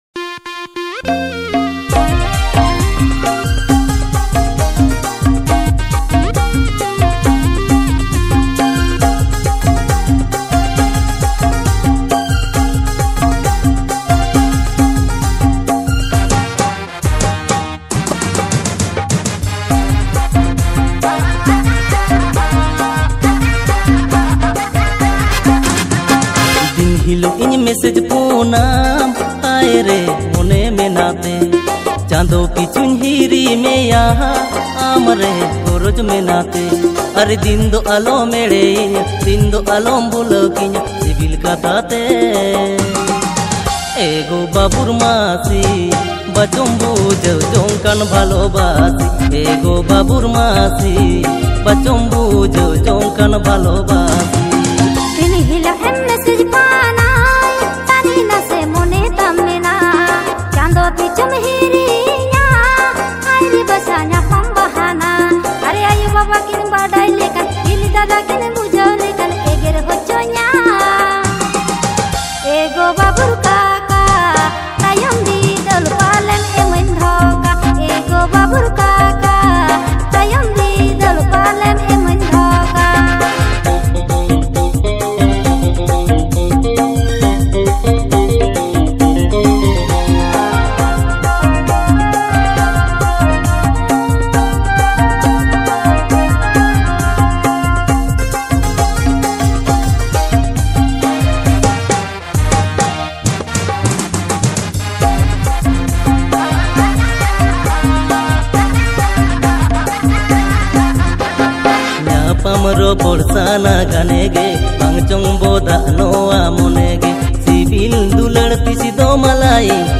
Santali song
• Male Artist